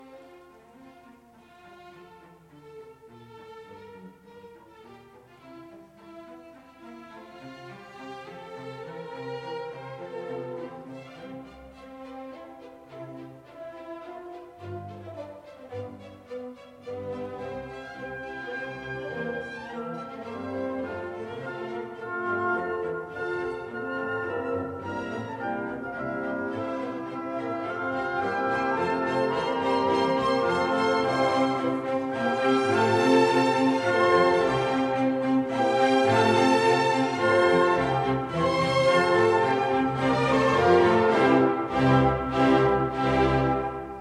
The movement continues with the second theme, which contains a fanfare-like sounding, very airy.
The coming development section changes the mood of the music. It starts with rising triads and then with the incoming distinctive tone of the oboes bring dramatic or rather melancholic tone. Listen to the nice little dialogue between the wind and string instruments towards the end of the clip!
In the recapitulation we hear theme 1 again decorated with countermelody. Observe how violin, viola and then winds are cooperating!